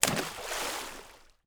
SPLASH_Small_04_mono.wav